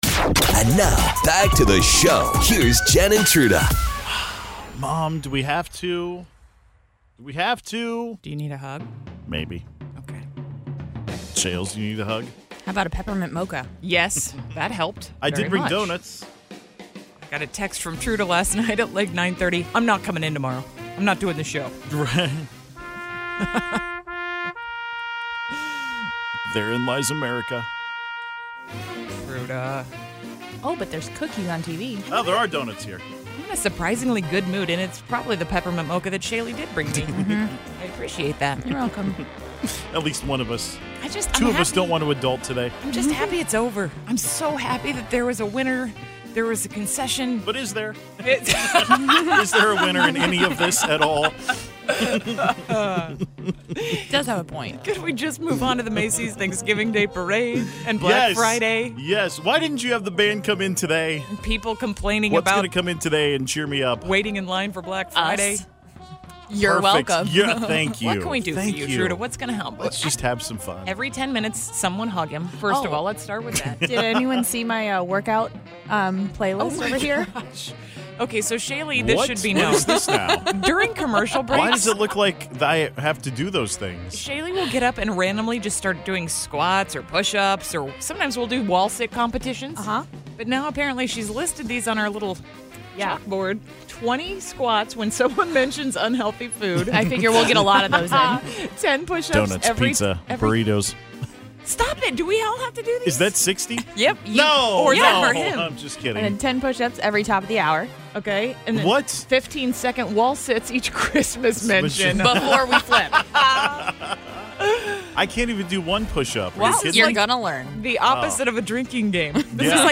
Where do you take first dates? A listener called up and suggested the Nelson Atkins Museum -- great!